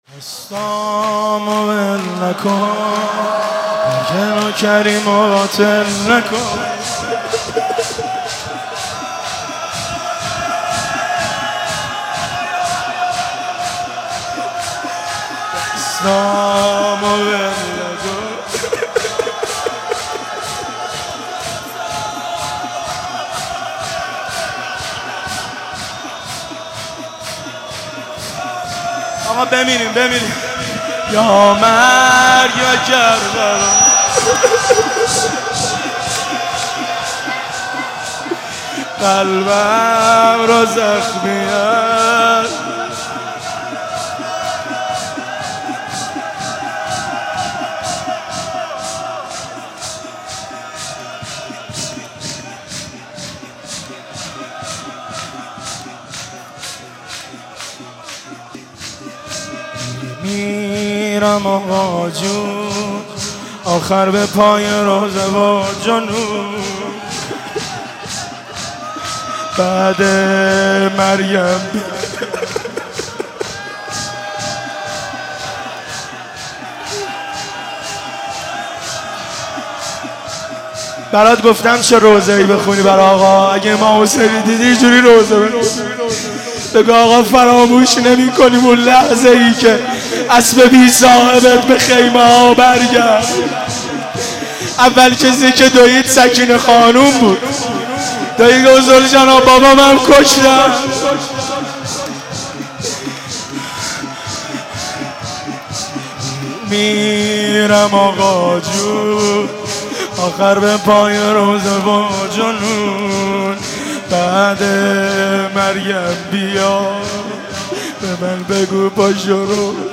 مناسبت : وفات حضرت زینب سلام‌الله‌علیها
قالب : شور